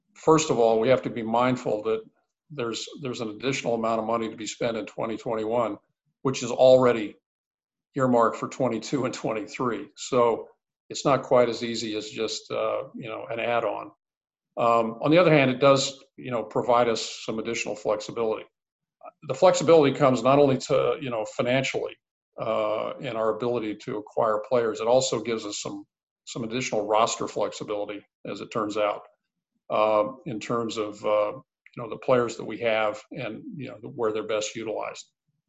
Sandy Alderson made 2 major announcements in his Zoom call with reporters yesterday–one was expected and the other was a bit surprising.